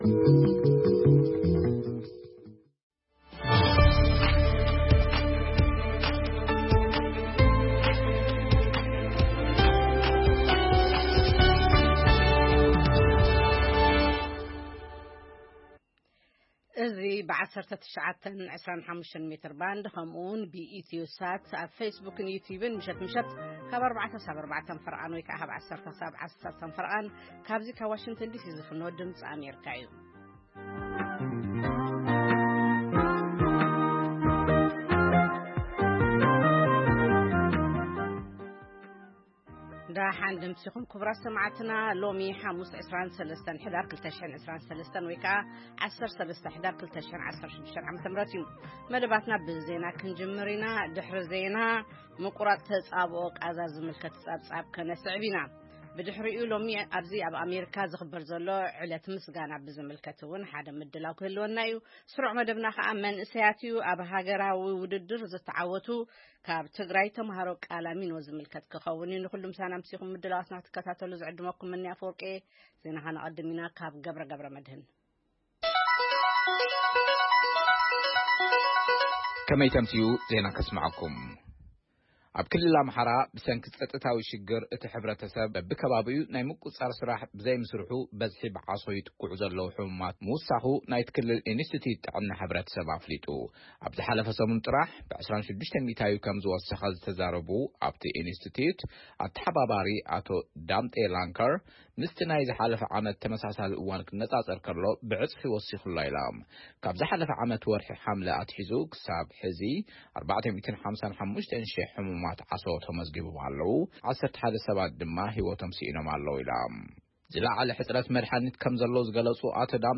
ፈነወ ትግርኛ ብናይ`ዚ መዓልቲ ዓበይቲ ዜና ይጅምር ። ካብ ኤርትራን ኢትዮጵያን ዝረኽቦም ቃለ-መጠይቓትን ሰሙናዊ መደባትን ድማ የስዕብ ። ሰሙናዊ መደባት ሓሙስ፡ መንእሰያት/ ጥዕና